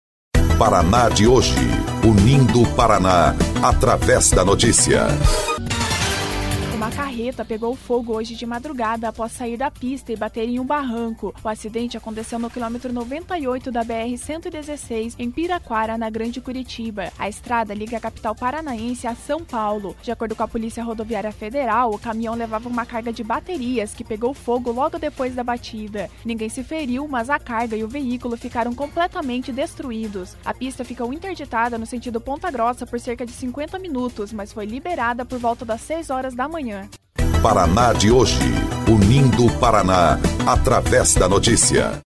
25.01 – BOLETIM – Carreta pega fogo na BR-116